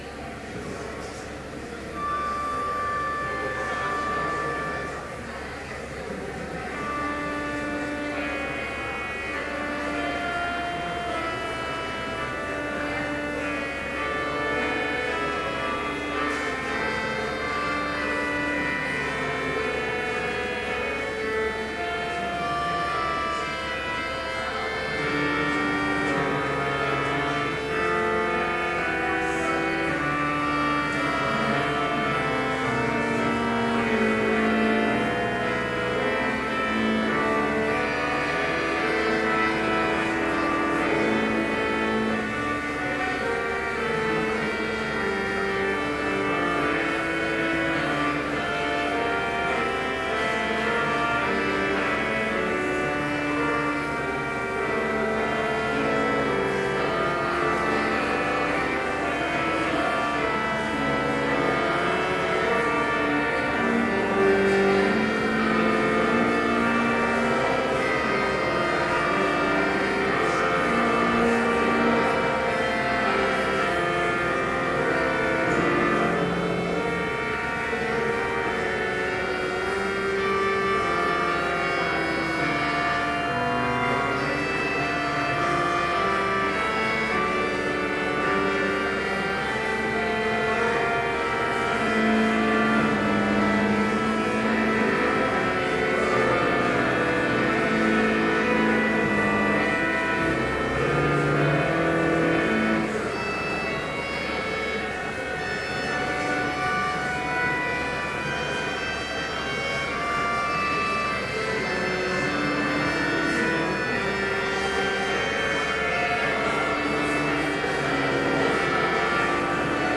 Complete service audio for Chapel - September 20, 2012